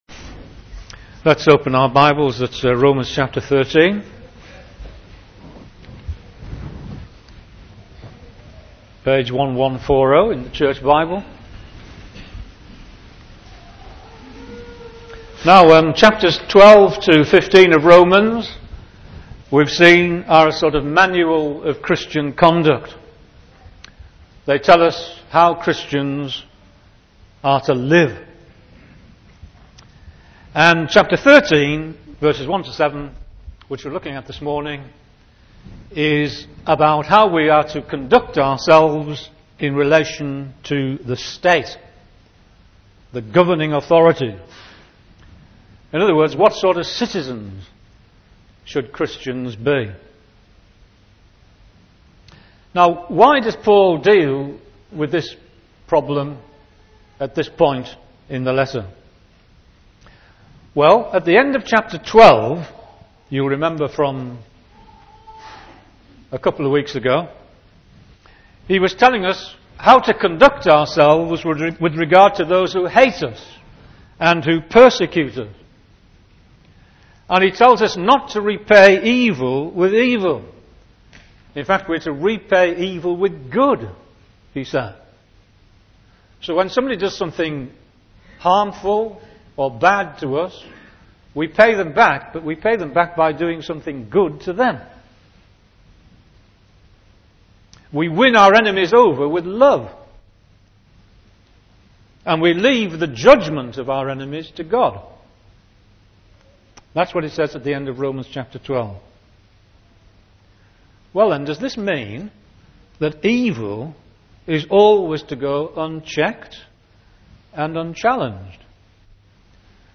Sermons and Talks recorded at Parr Street Evangelical Church, Kendal, Cumbria, UK